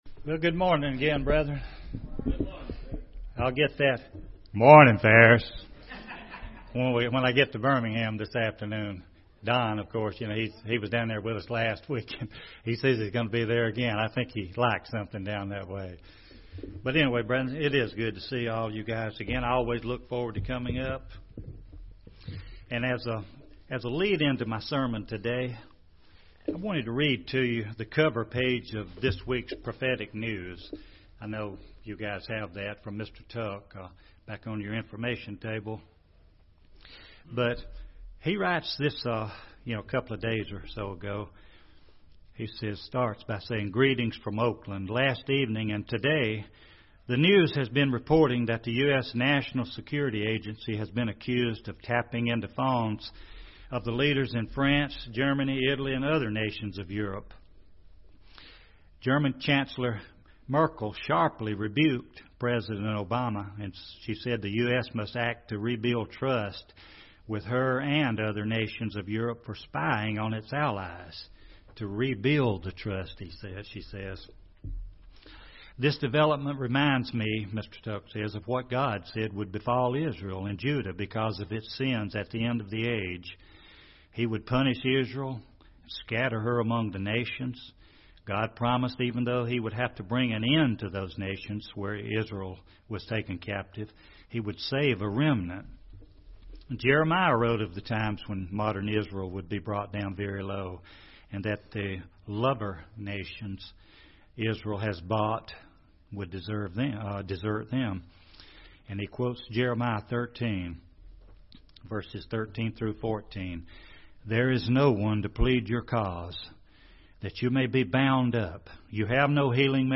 UCG Sermon Studying the bible?
Given in Birmingham, AL Gadsden, AL